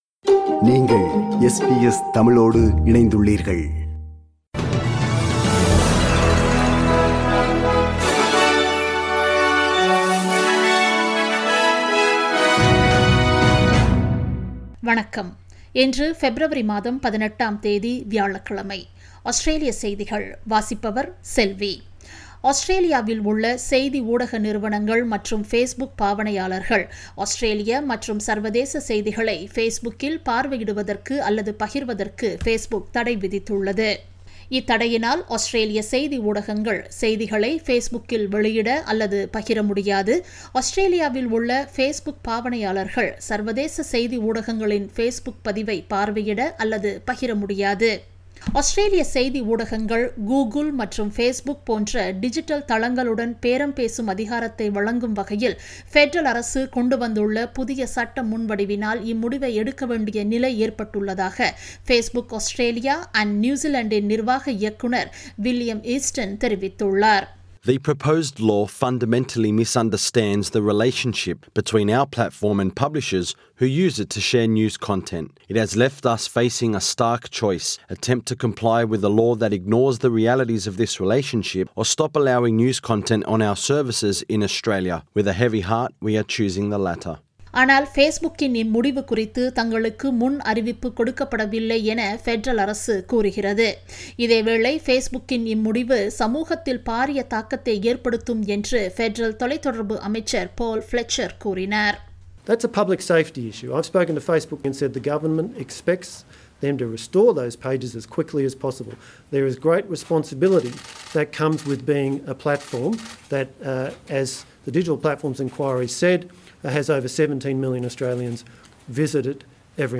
SBS தமிழ் ஒலிபரப்பின் இன்றைய (வியாழக்கிழமை 18/02/2021) ஆஸ்திரேலியா குறித்த செய்திகள்.